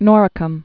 (nôrĭ-kəm, nŏr-)